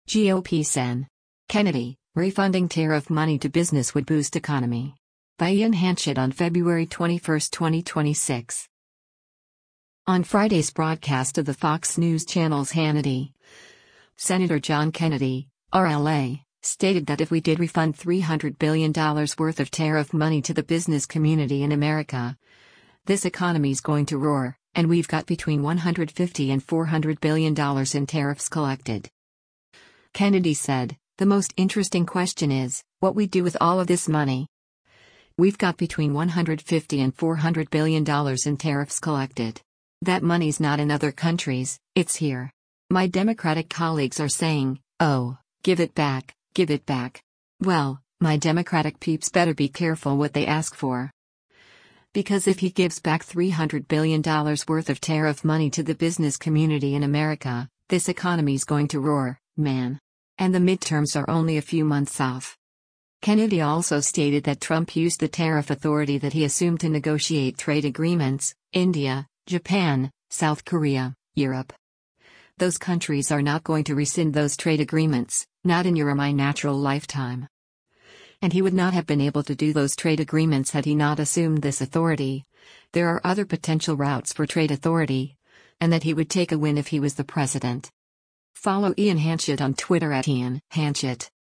On Friday’s broadcast of the Fox News Channel’s “Hannity,” Sen. John Kennedy (R-LA) stated that if we did refund “300 billion dollars worth of tariff money to the business community in America, this economy’s going to roar,” and “We’ve got between 150 and 400 billion dollars in tariffs collected.”